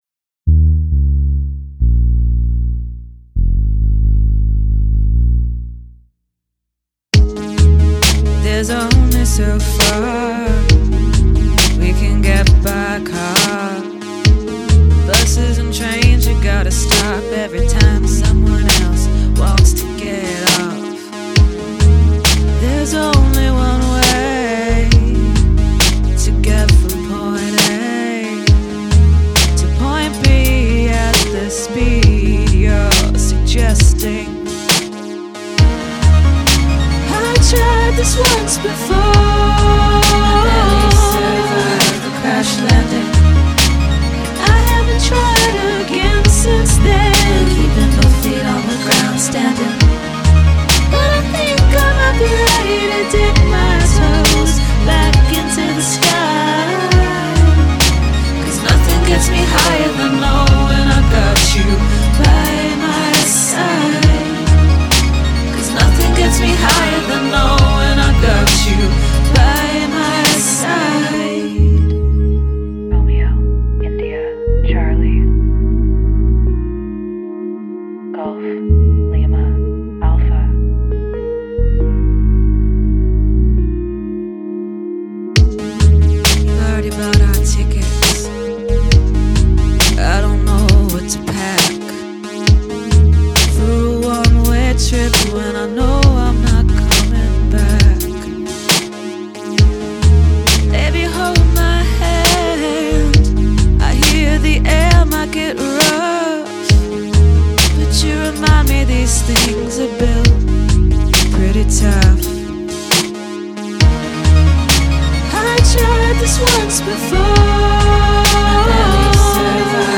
Guest Lead Vocals
I like how this flirts with Trip Hop.